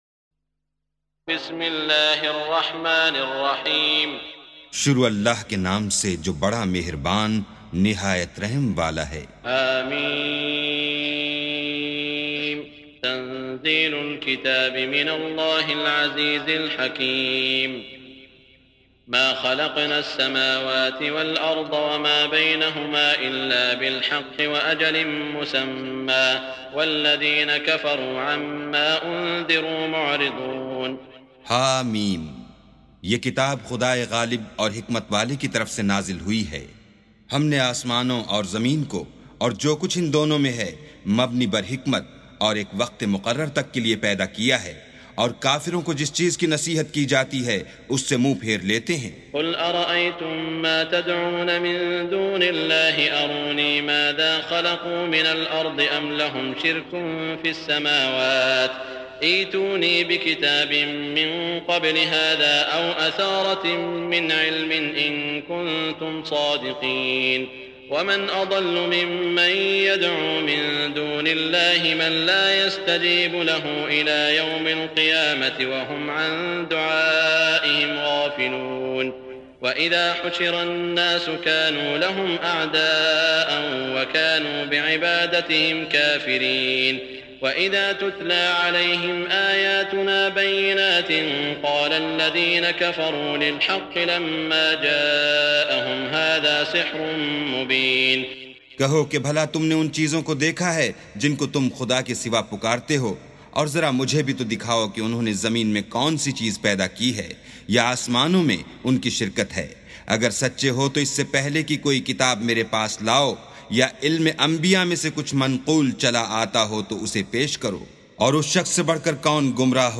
سُورَةُ الأَحۡقَافِ بصوت الشيخ السديس والشريم مترجم إلى الاردو